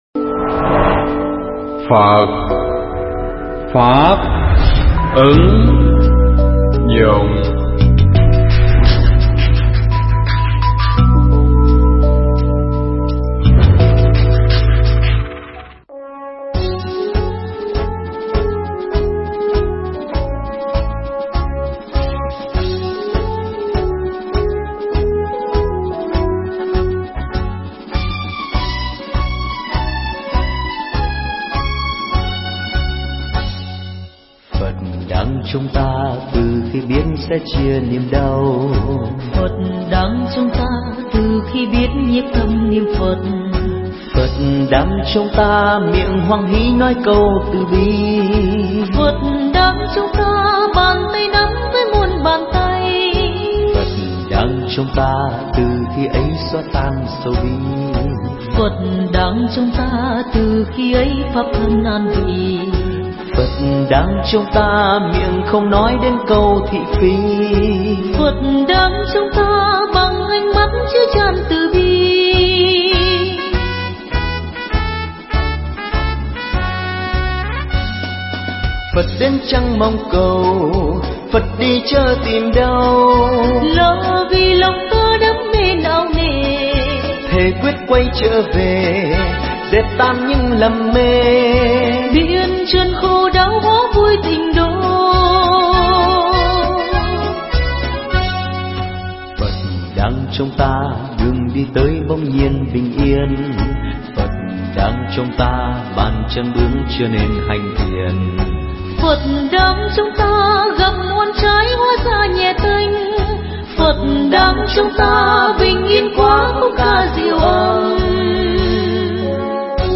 Nghe Mp3 thuyết pháp Suy Nghĩ Về Pháp Môn Tịnh Độ